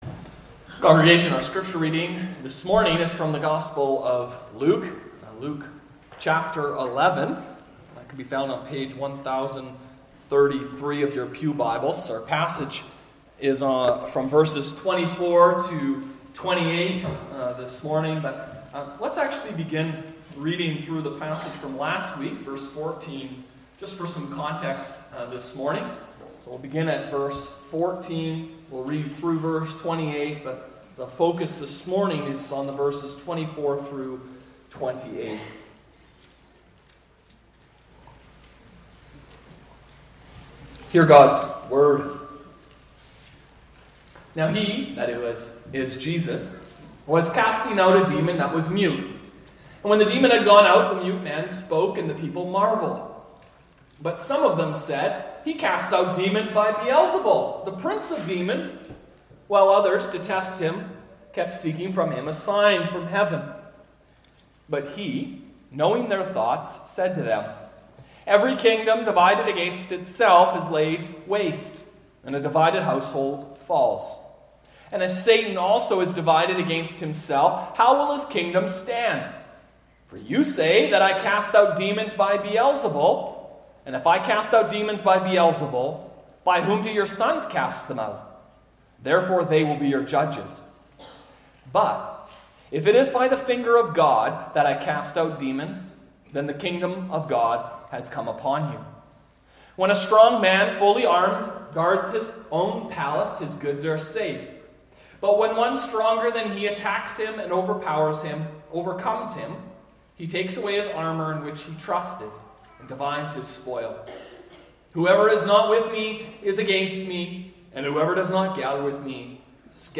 Play Rate Listened List Bookmark Get this podcast via API From The Podcast Weekly Sunday Worship at Emmanuel United Reformed Church located in Barrhead, Alberta Join Podchaser to...